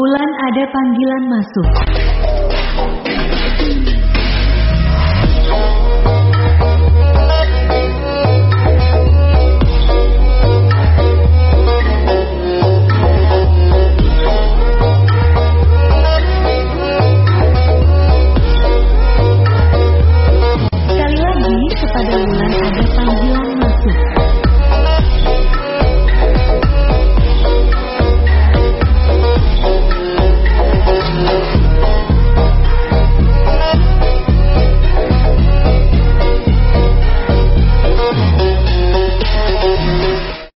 Dibuat Sendiri Nada Dering WA Sebut Nama
Kategori: Nada dering